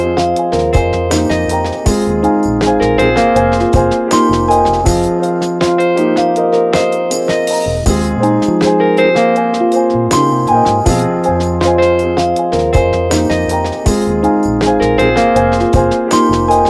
Featured in Electro RIngtones